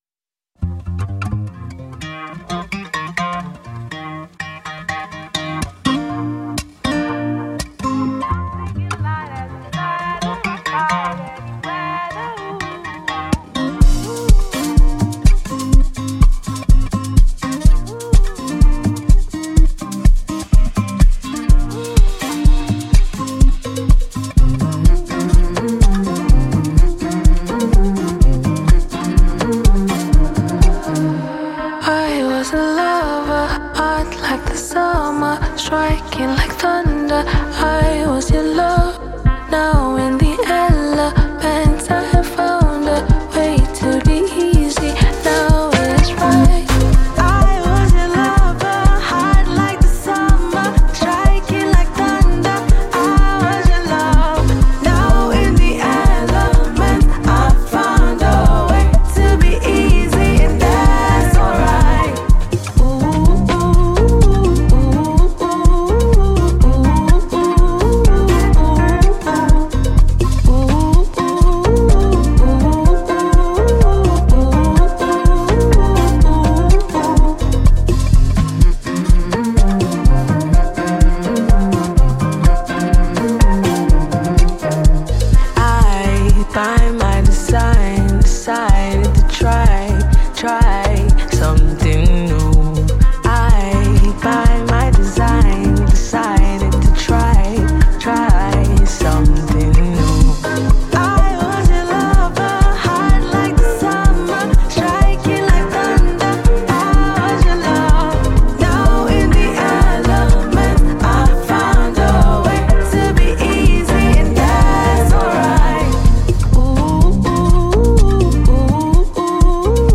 Afrobeats
With nice vocals and high instrumental equipments